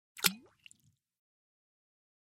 Звуки лимона, лайма
Откройте для себя яркие звуки лимона и лайма: сочное разрезание, брызги сока, хруст свежих долек.
Лимон - Альтернативный вариант